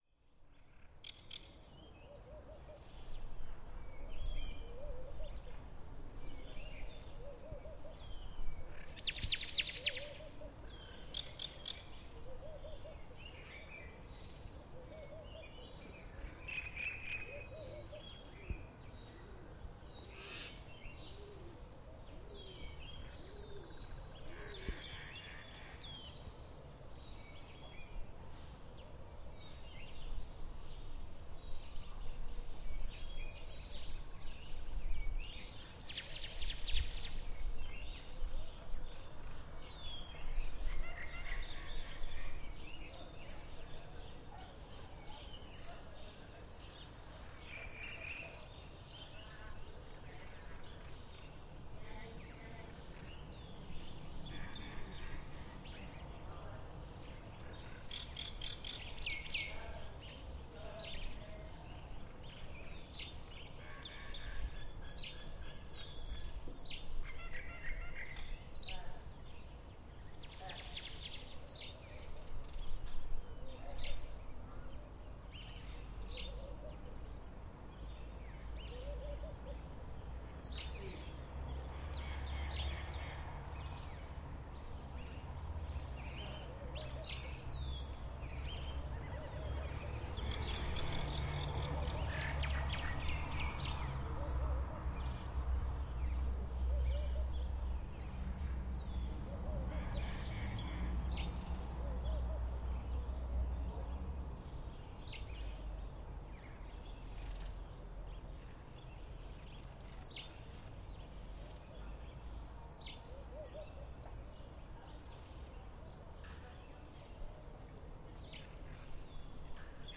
Tag: 背景声 氛围 环境 ATMO ATMOS 背景 气氛